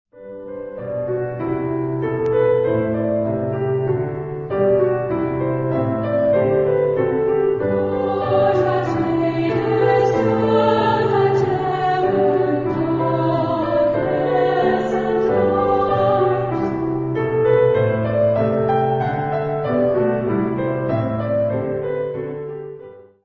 Genre-Style-Forme : Sacré ; Motet ; Hymne (sacré)
Caractère de la pièce : calme
Type de choeur : SAH  (3 voix mixtes )
Instruments : Piano (1)
Tonalité : sol majeur